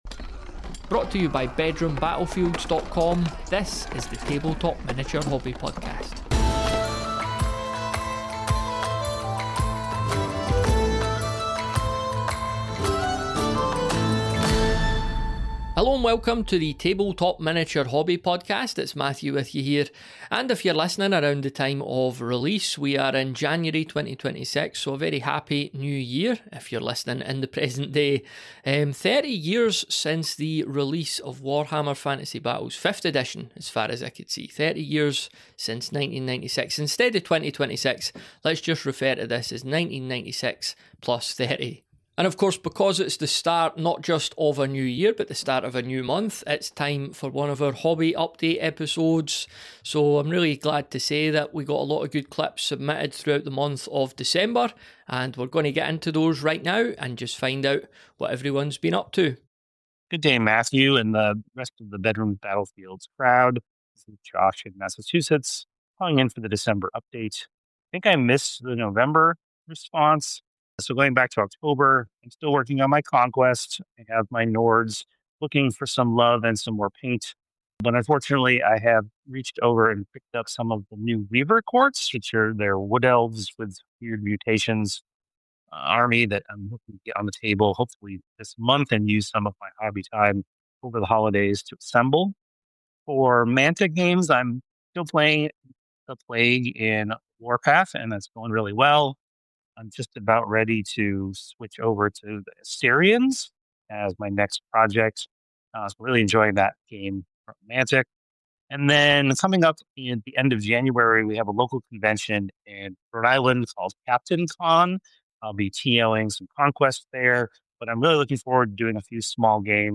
Episode 24 marks the final episode of the year and the 12 month anniversary of Beyond the Battlefield. In this deeply honest conversation